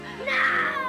Daniela Lujan Gritando Téléchargement d'Effet Sonore
Daniela Lujan Gritando Bouton sonore